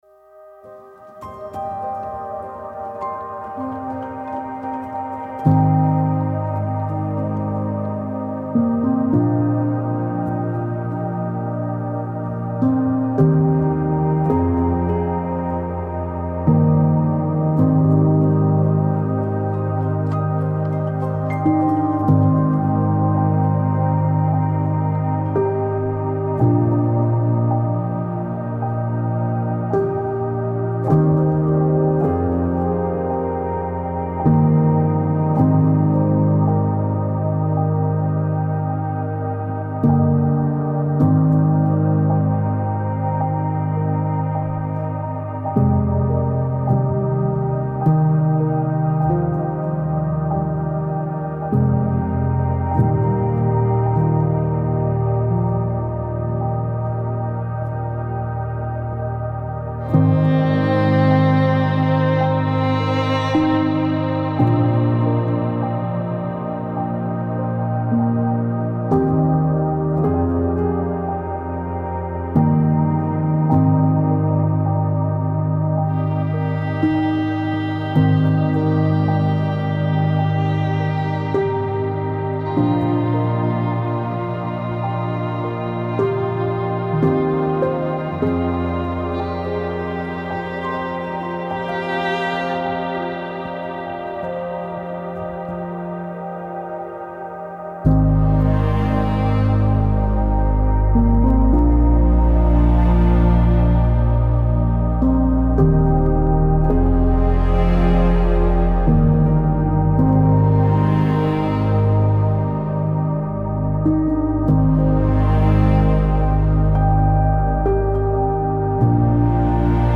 آرامش‌بخش